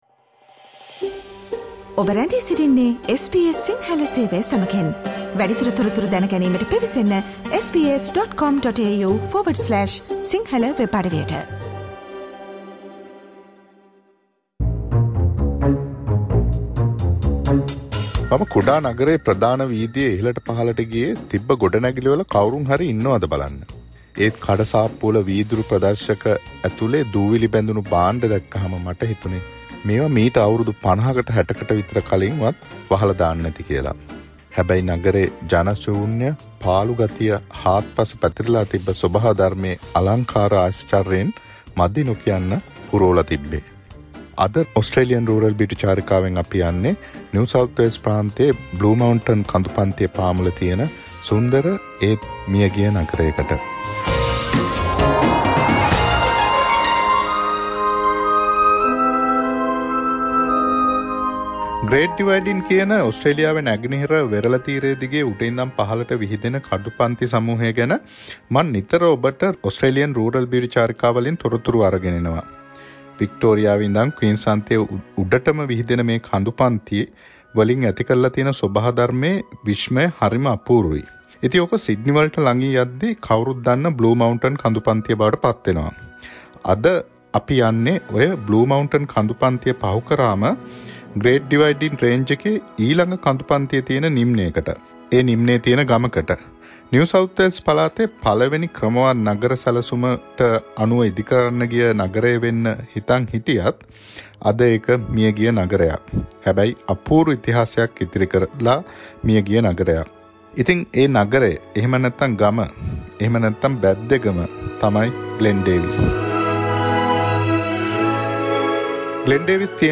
The monthly radio tour of SBS Sinhala Radio, taking you to Glen Davis in NSW, an old local town that used to be an oil mining city in Australia